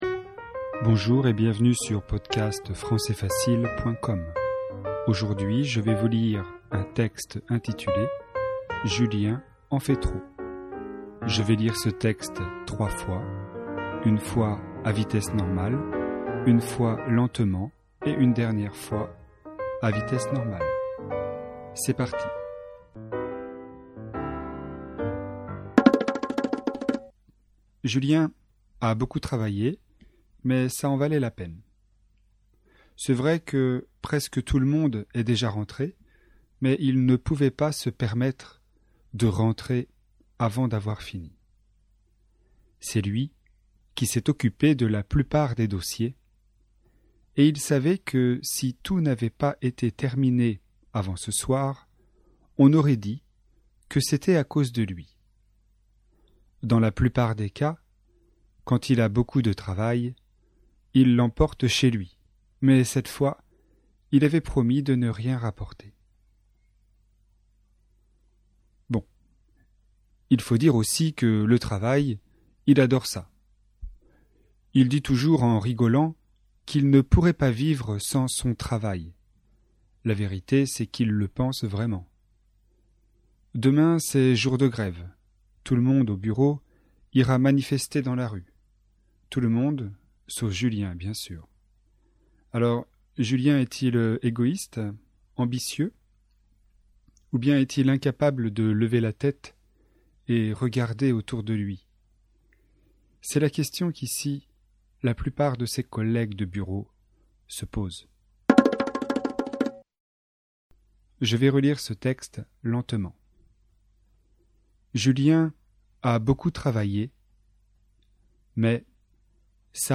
Texte FLE et audio, niveau intermédiaire (A2).